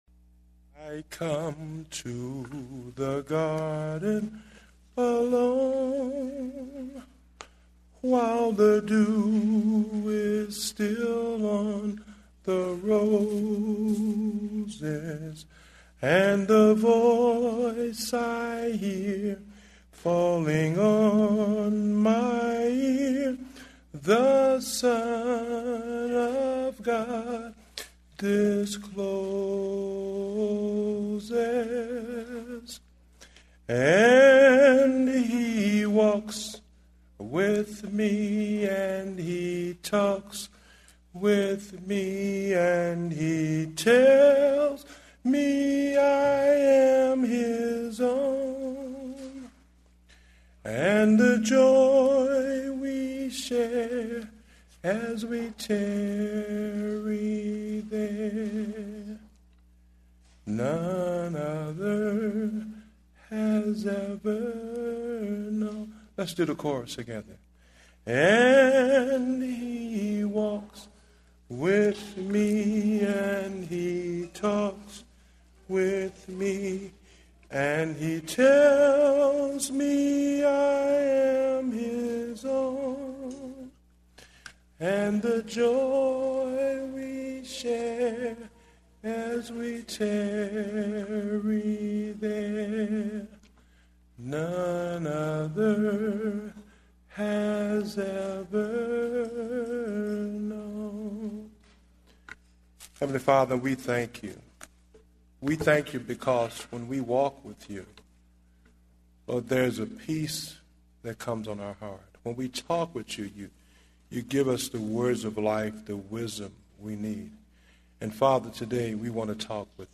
Date: January 24, 2010 (Morning Service)